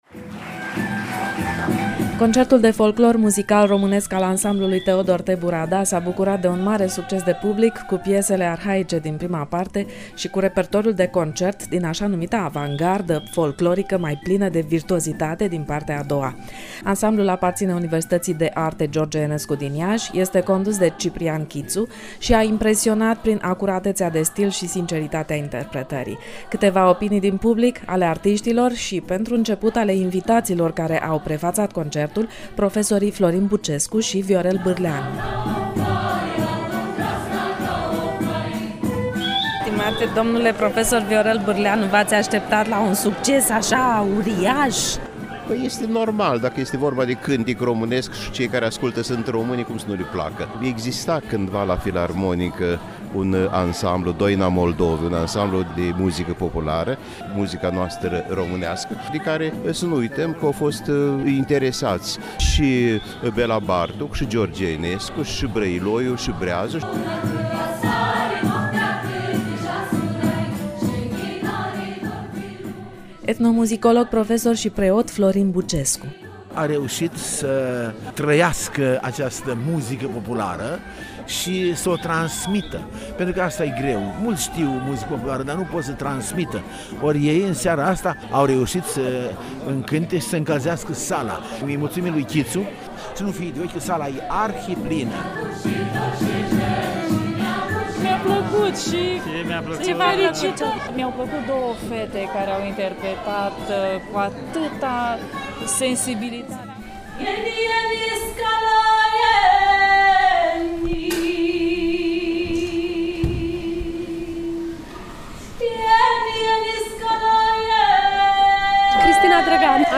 Opiniile publicului şi ale criticilor